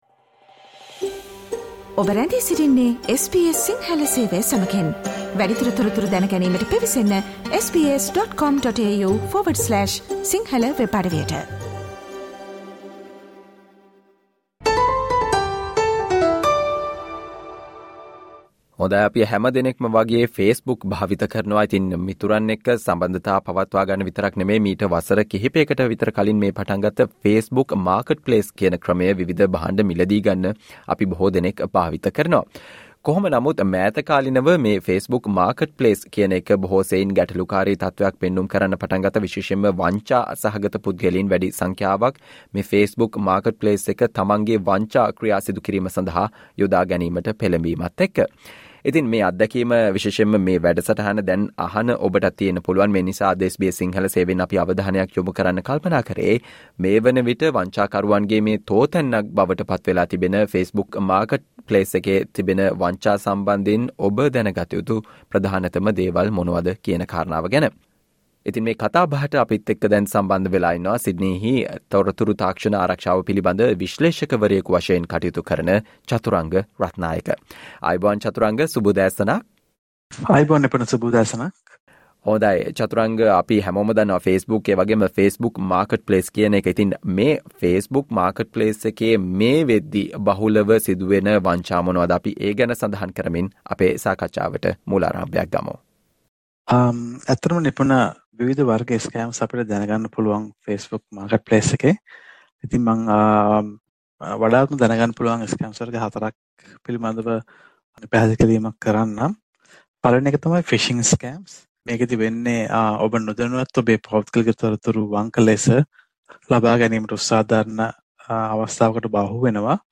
Listen to the SBS Sinhala discussion on how to avoid and report Facebook Marketplace scams